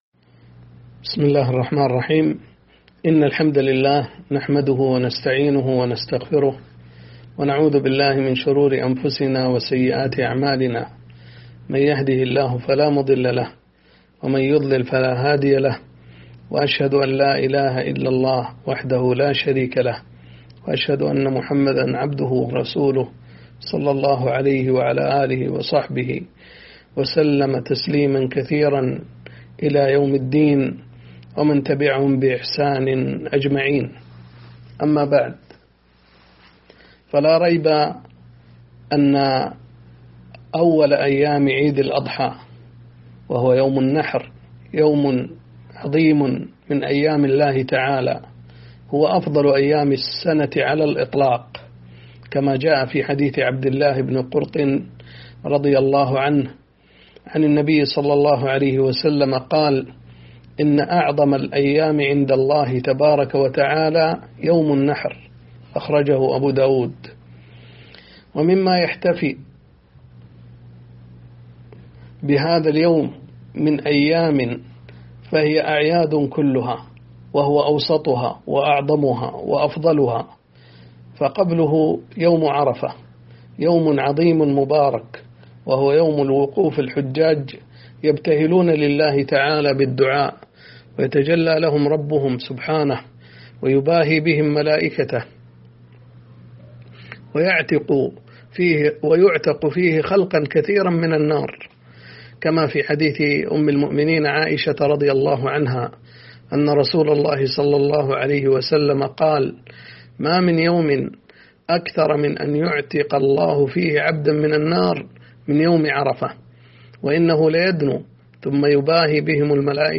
فضل يوم النحر وأيام التشريق - كلمة